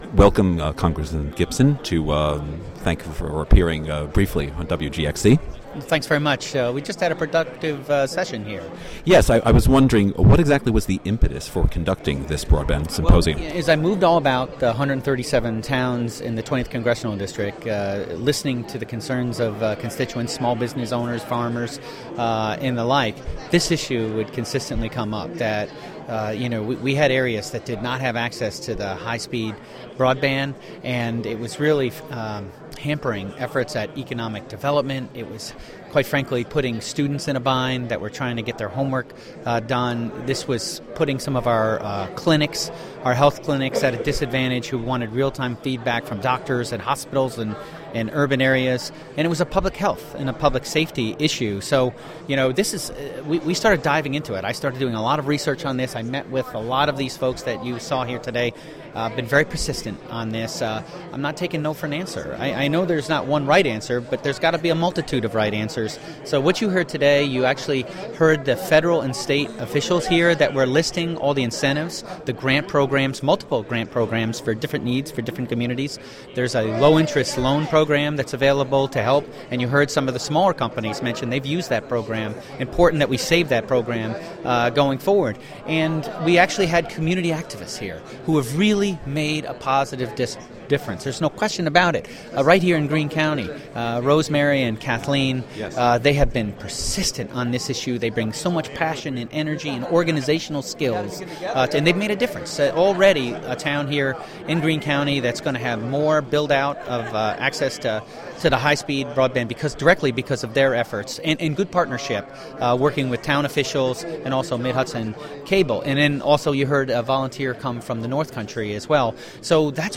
U.S. Representative Chris Gibson (R-Kinderhook) talks at "Rural Broadband" conference in Catskill. (Audio)
Interview